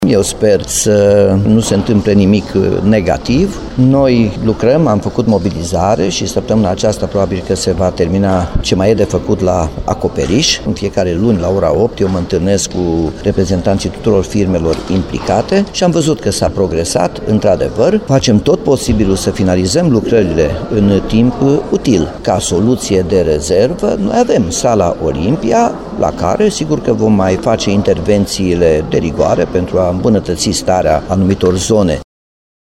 Noua sală polivalentă a Timișoarei ar putea fi finalizată până la vară, când orașul va găzdui meciuri de la Campionatul European de baschet pentru senioare. Cel puțin așa speră primarul Timișoarei, Nicolae Robu, care precizează, însă, că există și o soluție de avarie.